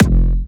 EDM Kick.wav